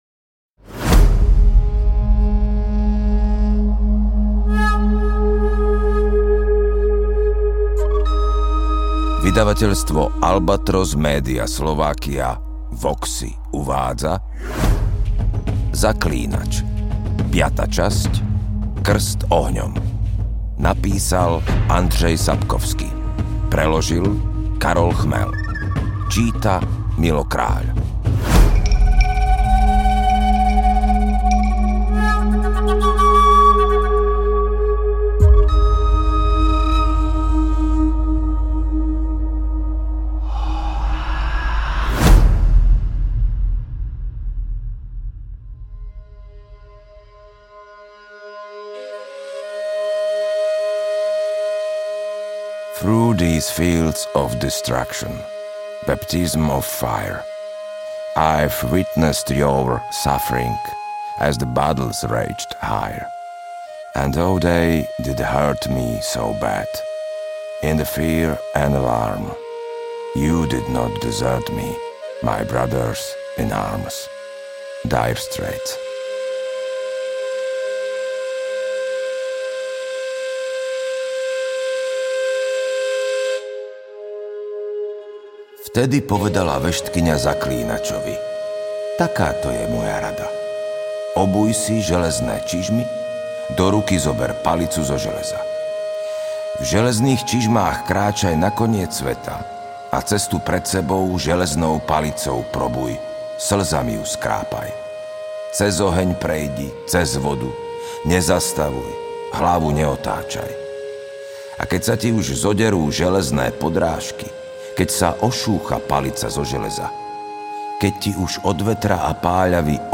AudioKniha ke stažení, 51 x mp3, délka 15 hod. 11 min., velikost 839,4 MB, slovensky